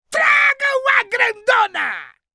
Play, download and share GRANDONA!! original sound button!!!!
pt_br_ziggs.mp3